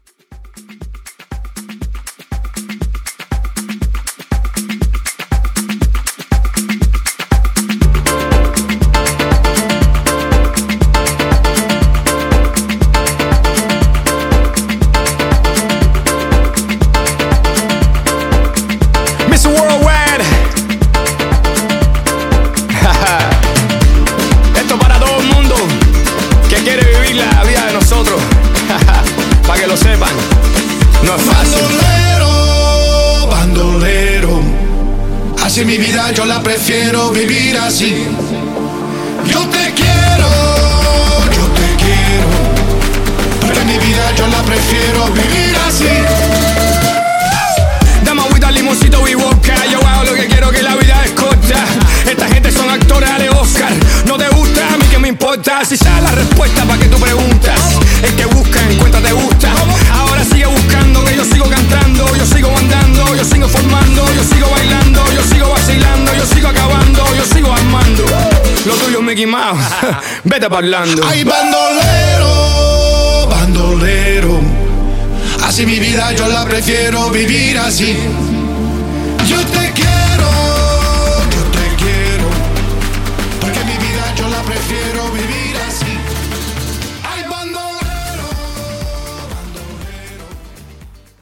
Genre: LATIN
Clean BPM: 120 Time